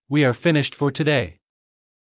ｳｨｰ ｱｰ ﾌｨﾆｯｼｭﾄﾞ ﾌｫｰ ﾄｩﾃﾞｨ